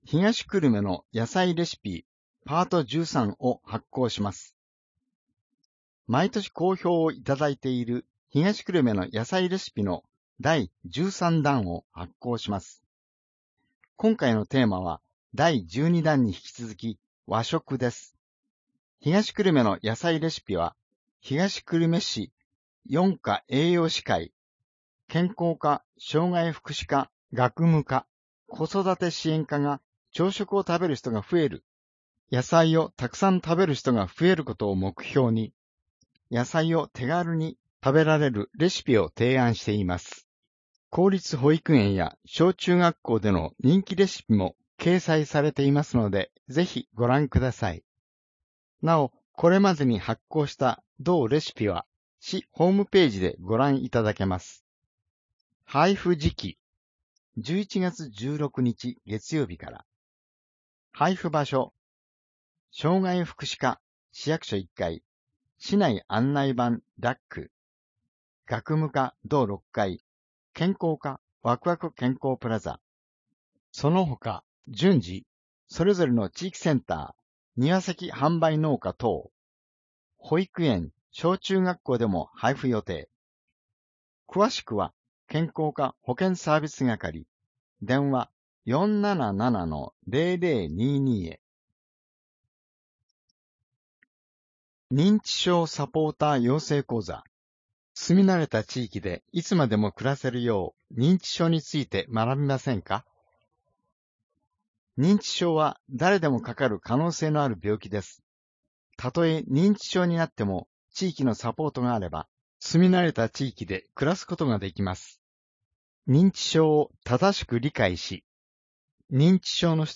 声の広報（令和2年11月1日号）